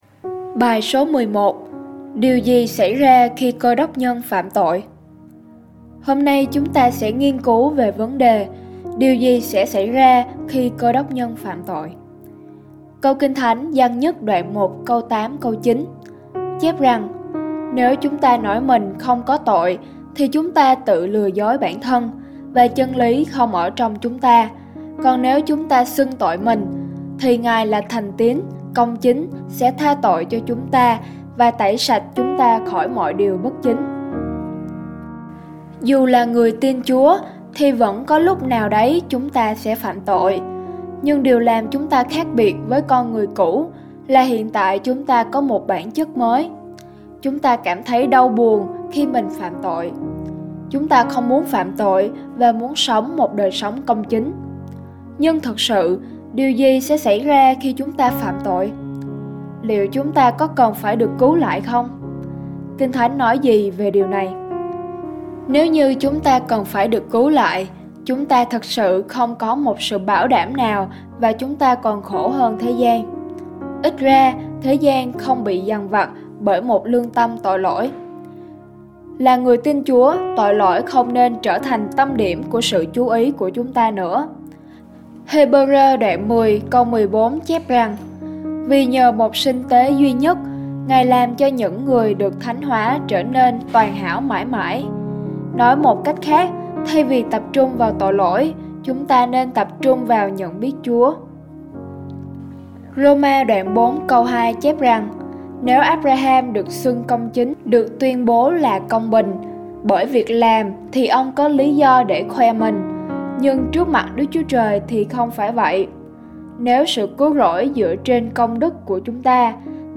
BÀI HỌC